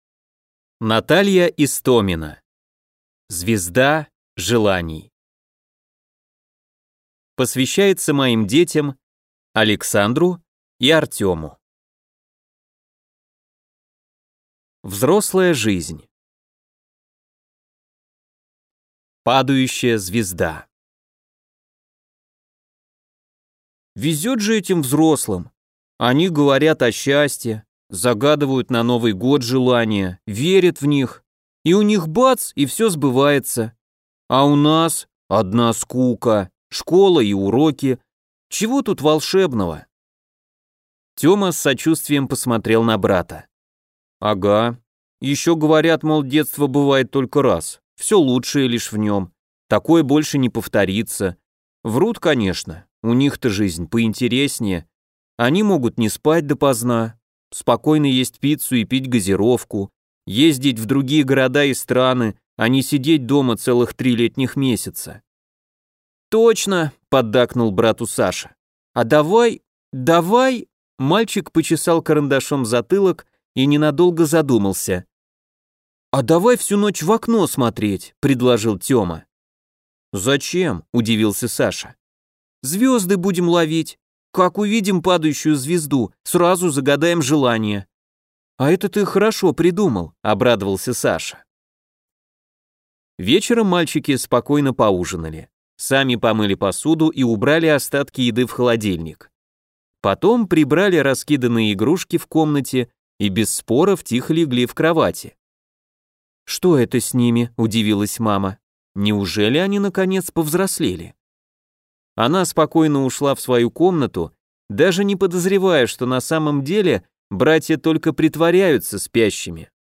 Аудиокнига Звезда желаний | Библиотека аудиокниг